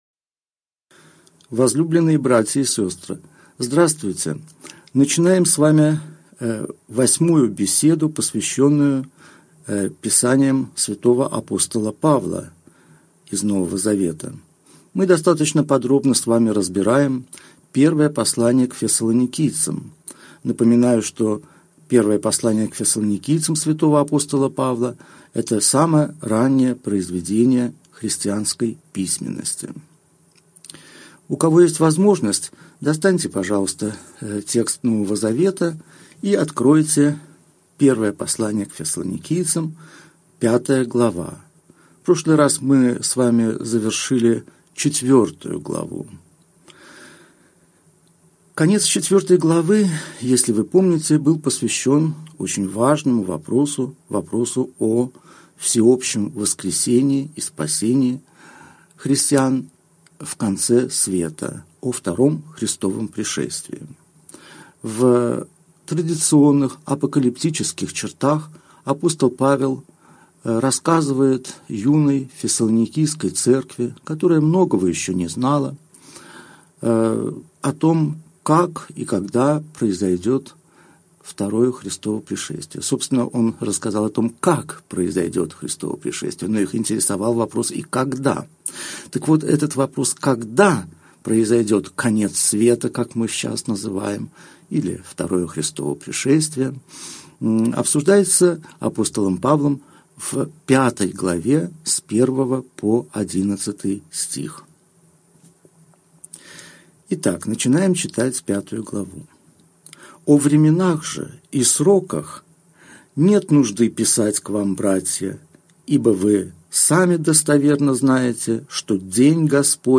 Аудиокнига Беседа 8. Первое послание к Фессалоникийцам. Глава 5 | Библиотека аудиокниг